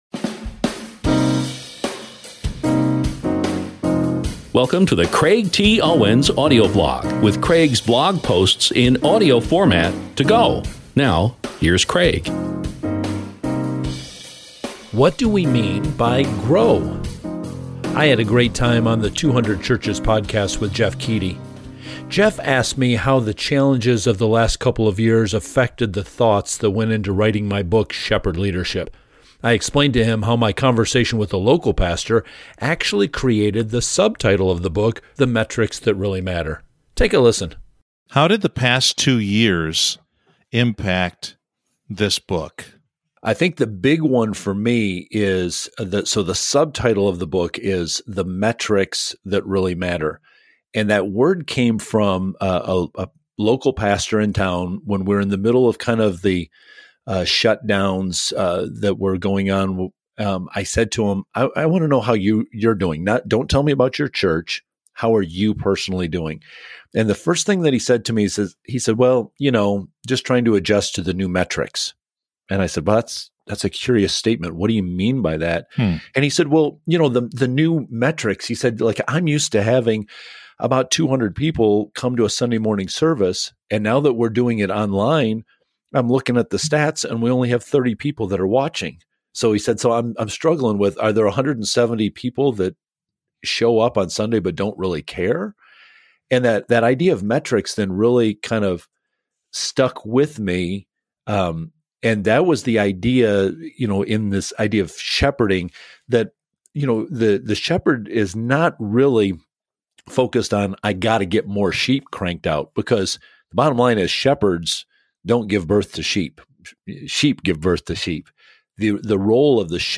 I’ll be sharing more clips from this 200churches interview soon, so please stay tuned.